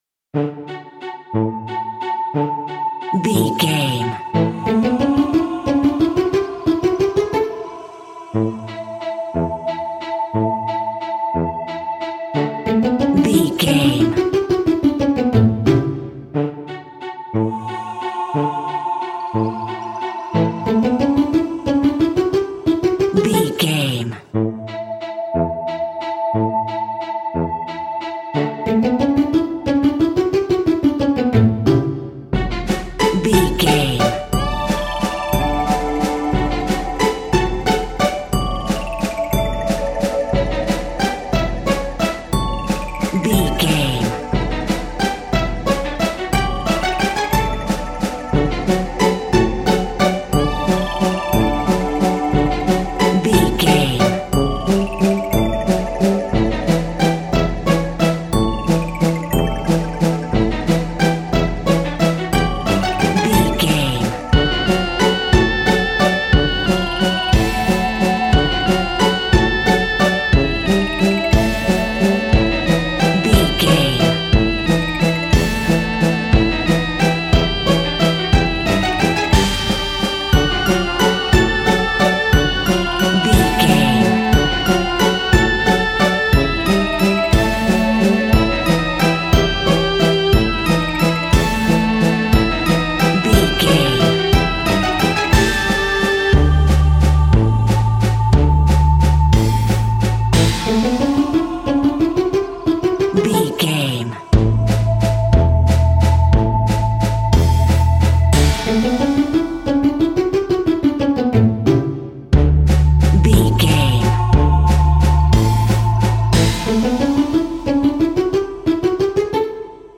Aeolian/Minor
ominous
dark
eerie
playful
strings
synthesiser
percussion
spooky
instrumentals
horror music